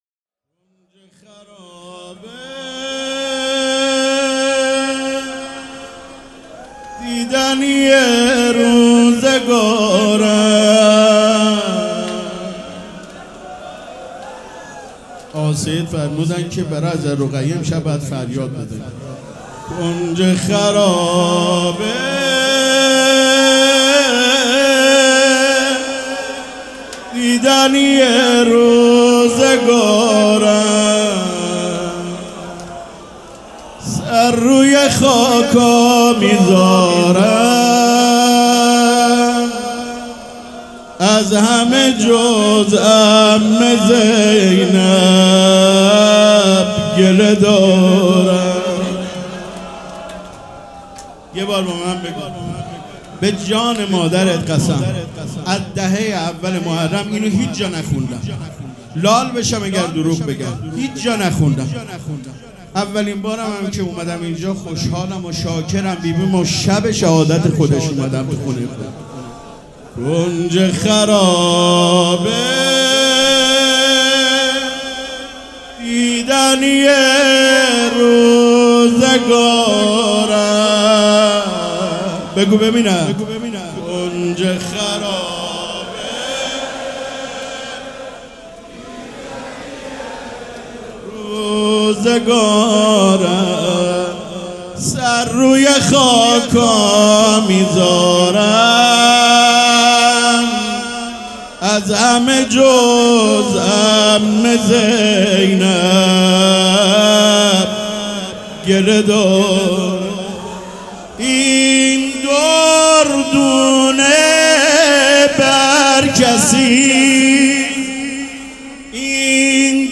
روضه بخش سوم
سبک اثــر روضه
مراسم عزاداری شب سوم